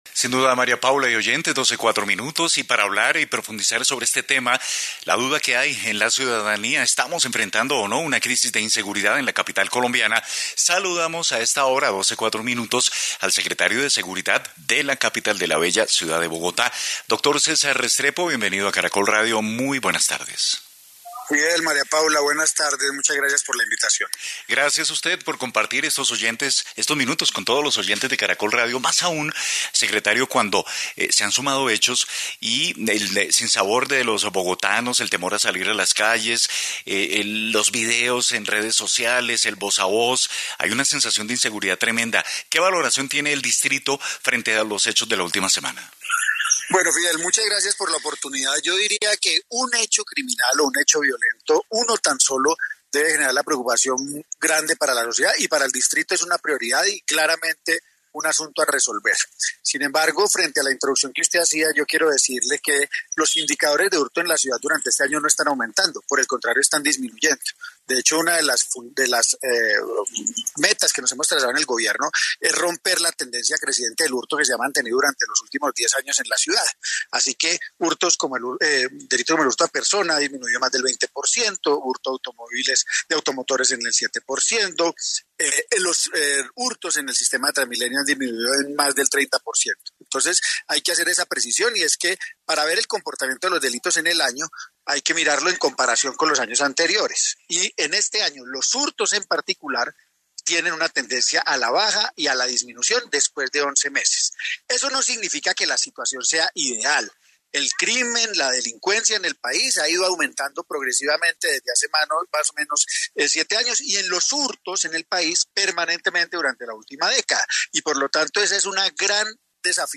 En entrevista para el noticiero del Medio Dia de Caracol Radio el secretario de Seguridad de Bogotá, Cesar Restrepo, se refirió a los recientes hechos de inseguridad que se han registrado en la ciudad. Señaló también que este año los casos de hurto han demostrado una tendencia a la baja.